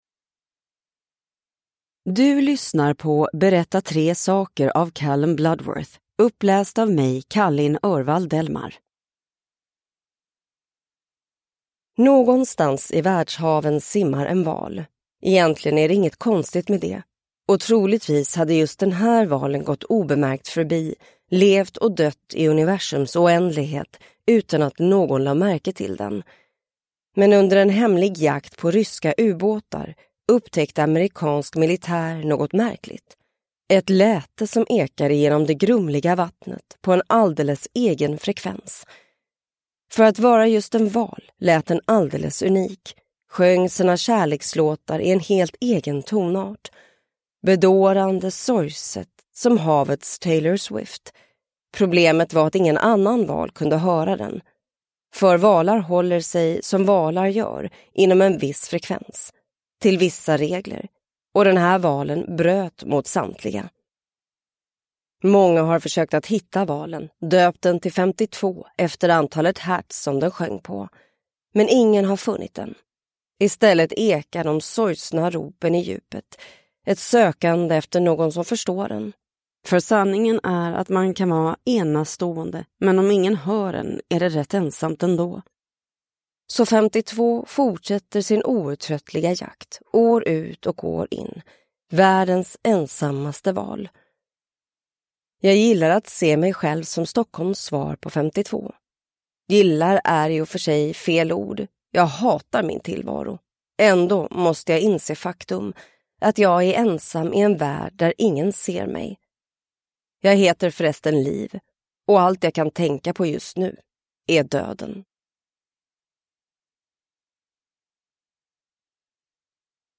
Berätta tre saker – Ljudbok